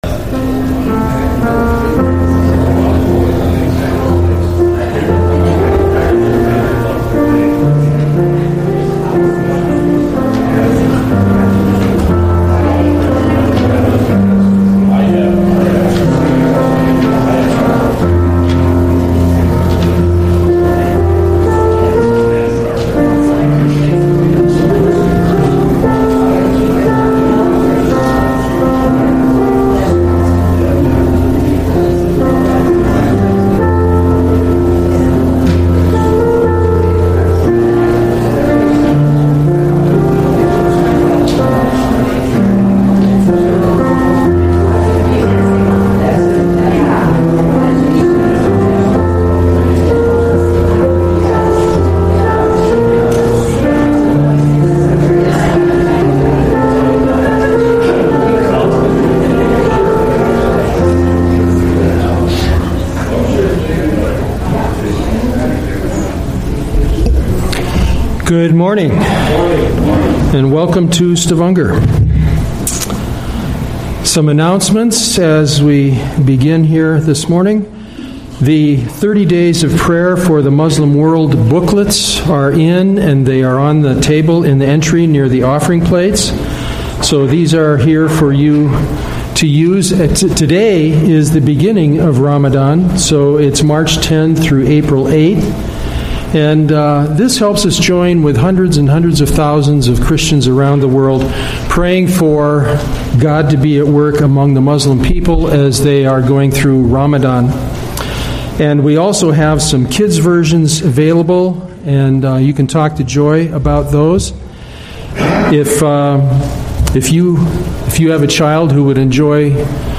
Sermons - Stavanger Lutheran Church
From Series: "Sunday Worship"